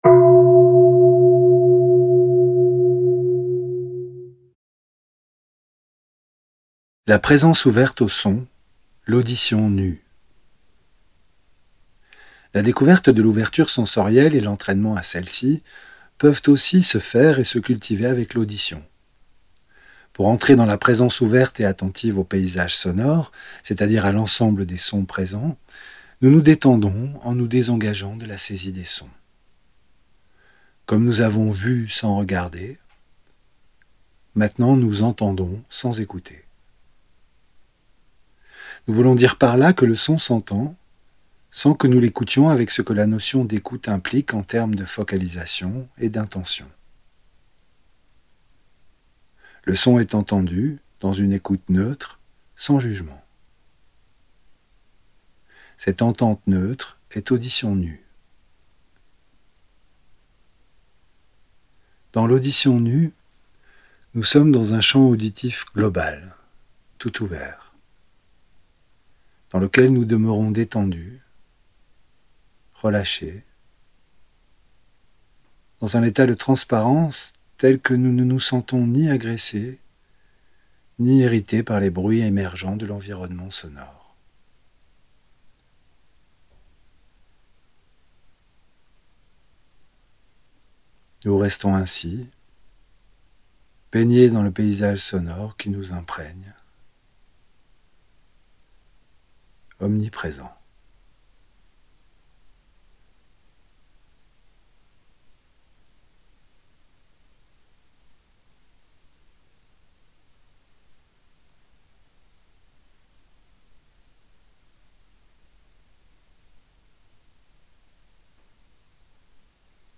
Audio femme